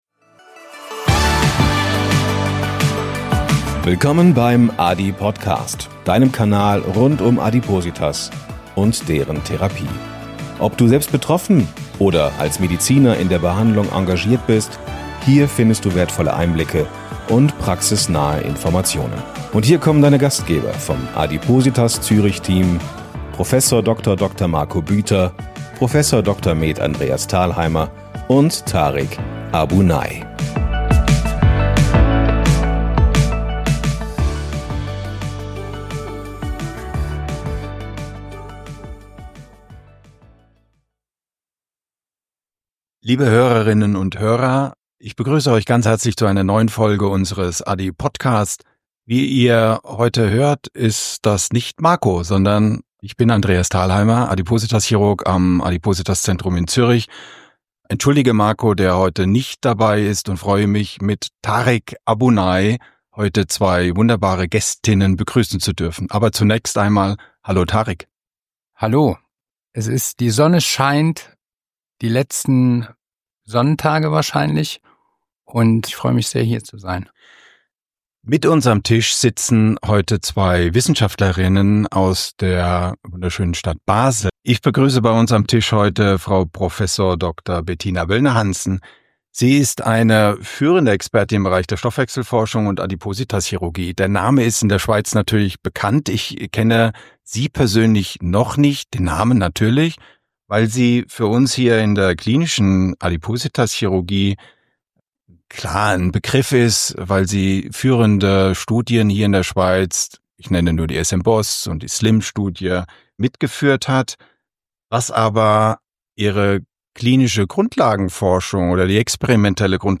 mit zwei der führenden Expertinnen